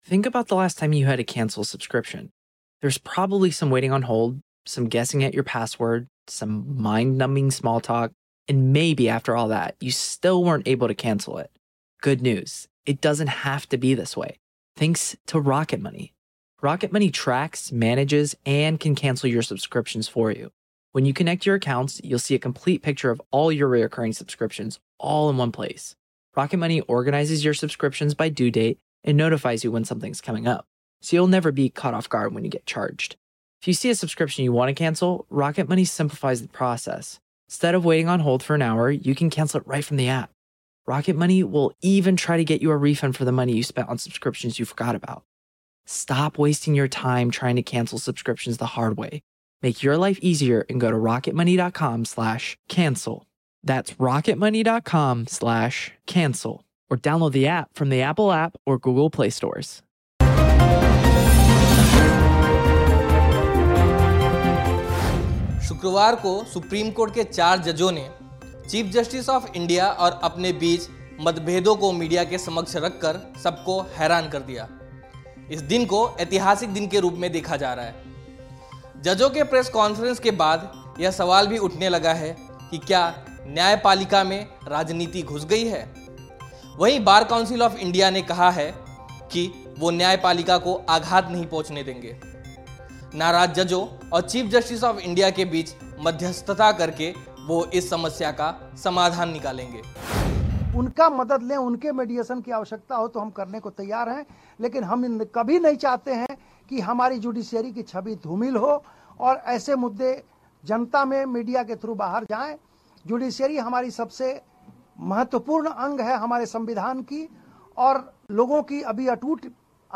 News Report / बार काउंसिल ऑफ़ इंडिया मध्यस्थता के जरिए नाराज जजों और मुख्य न्यायाधीश के बीच मतभेद का समाधान निकालेगी